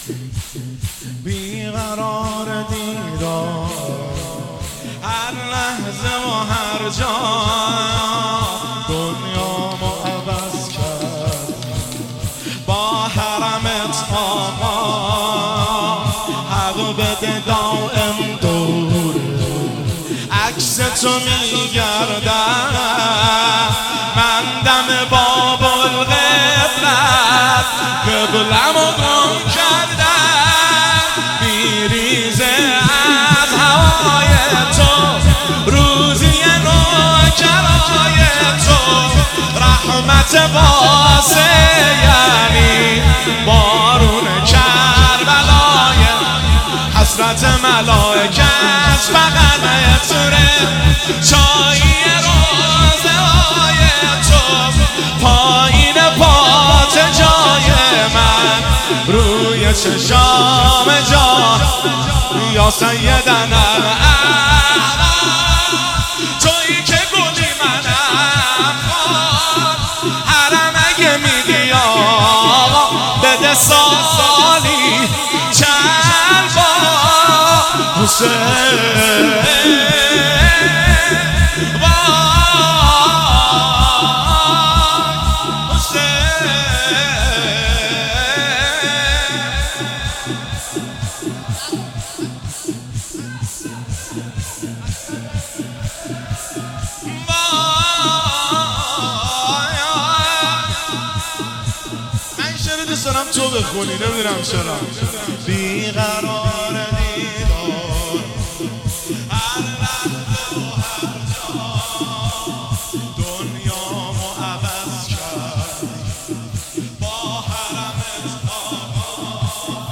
مداحی شور بی قرار دیدارم
جلسه هفتگی